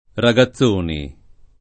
[ ra g a ZZ1 ni ]